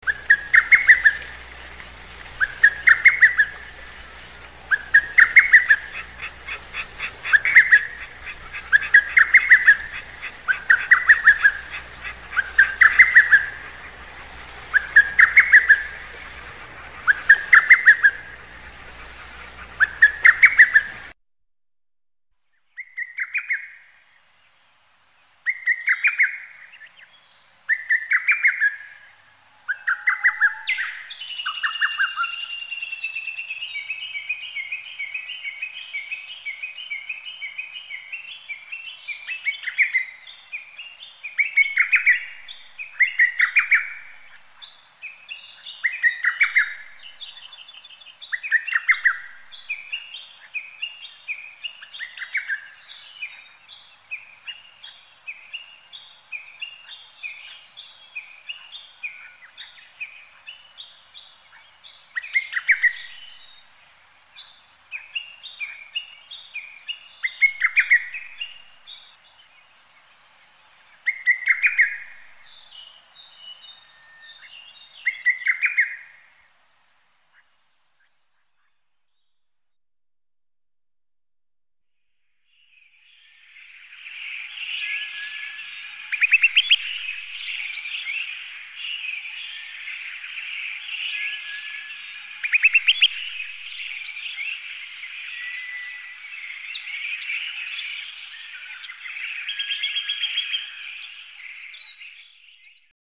이들을 눈으로 관찰하기는 어렵지만, 소리는 매우 크고, 뚜렷해서 쉽게 확인이 가능하지요.
두견이.mp3